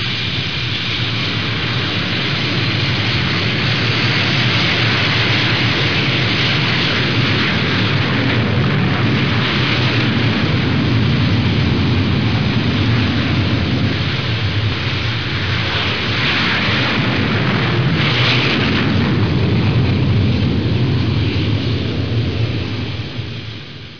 دانلود آهنگ طیاره 34 از افکت صوتی حمل و نقل
دانلود صدای طیاره 34 از ساعد نیوز با لینک مستقیم و کیفیت بالا
جلوه های صوتی